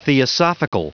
Prononciation du mot theosophical en anglais (fichier audio)
Prononciation du mot : theosophical